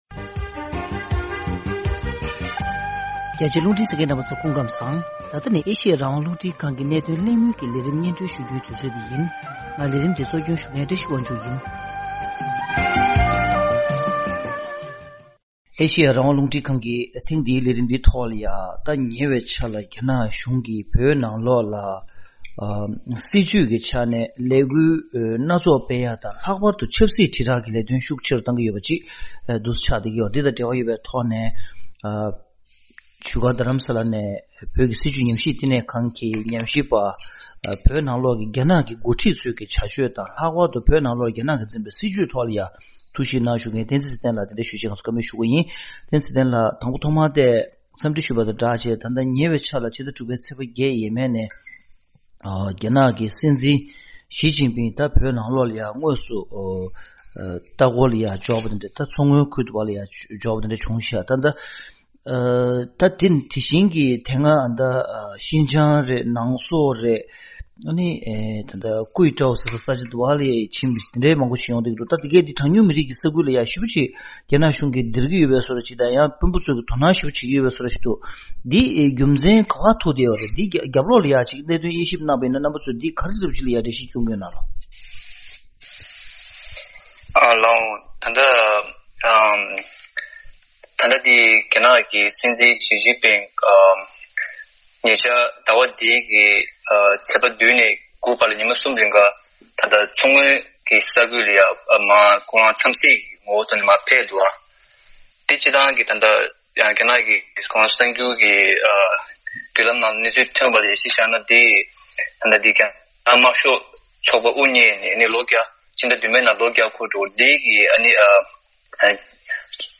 གཤམ་ལ་གནད་དོན་གླེང་མོལ་གྱི་ལས་རིམ་ནང་།